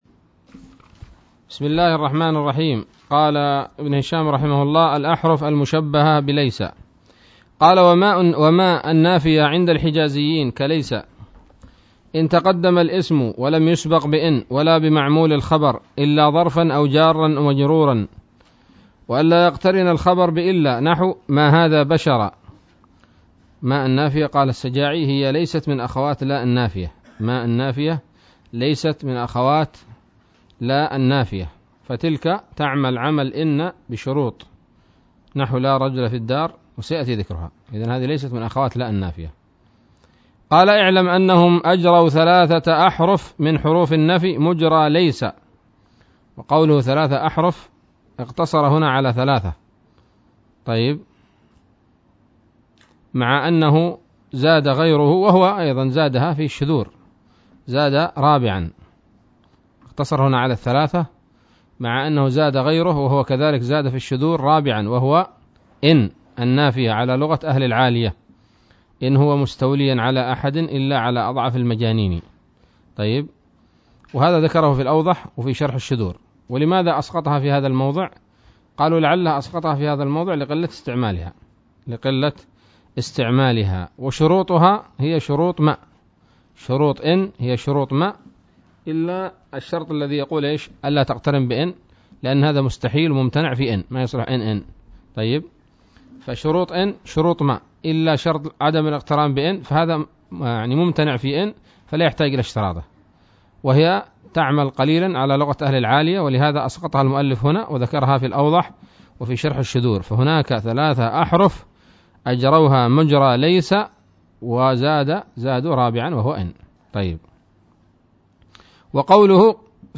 الدرس الستون من شرح قطر الندى وبل الصدى